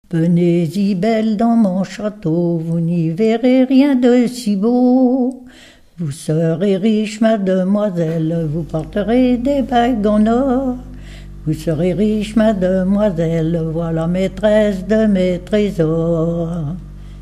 Localisation Sainte-Pazanne
Pièce musicale inédite